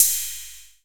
CYMBAL.wav